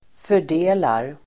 Uttal: [för_d'e:lar]